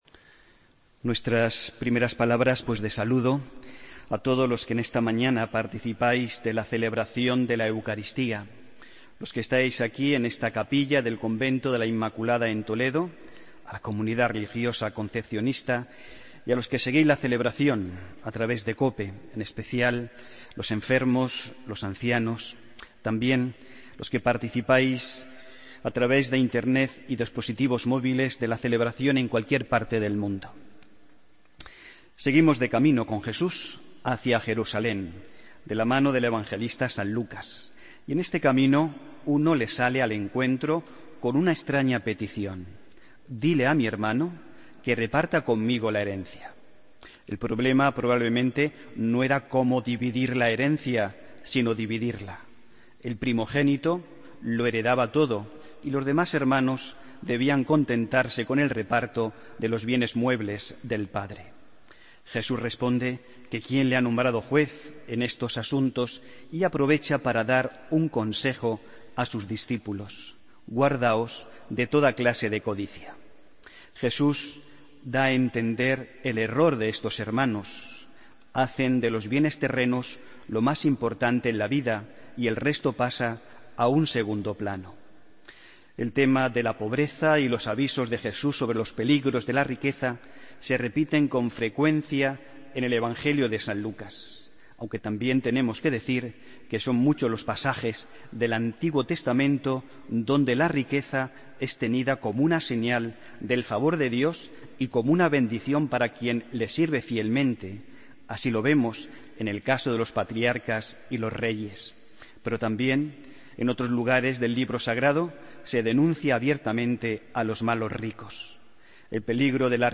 Homilía del domingo, 31 de julio de 2016